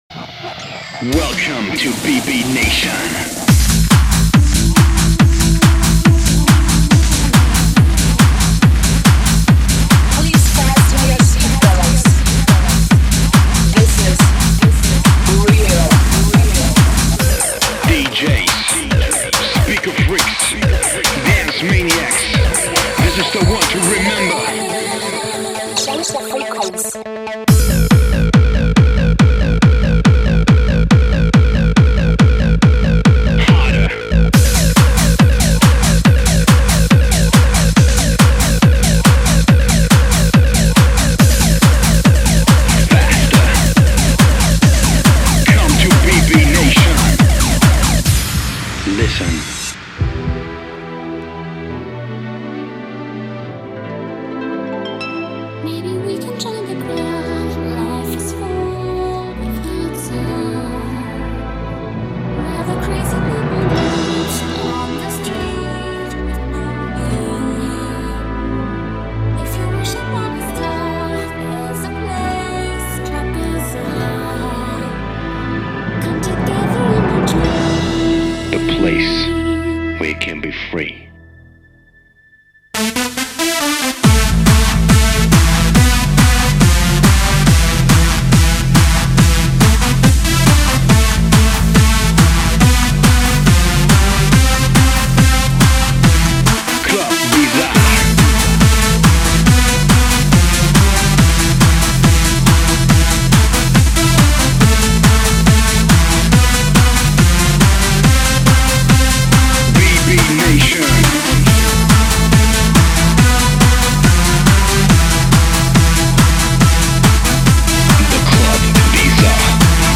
BPM35-140
Audio QualityPerfect (High Quality)
Comments[HARD DANCE]